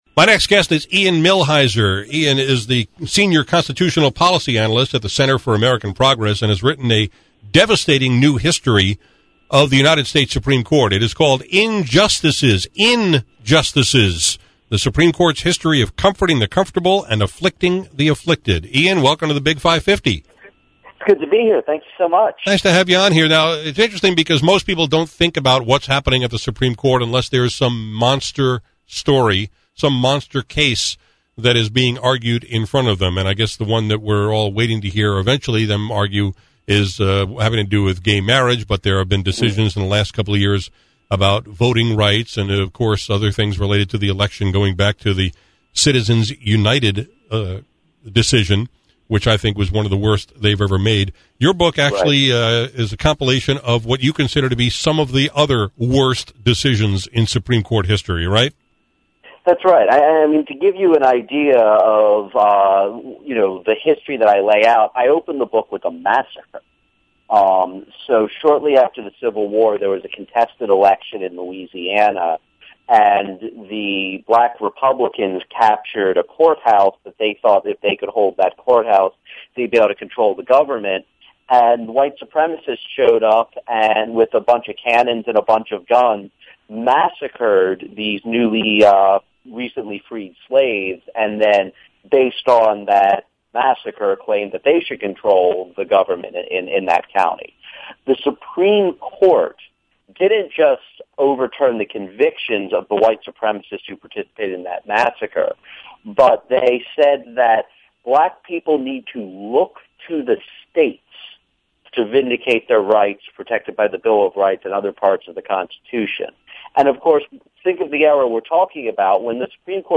Here’s my conversation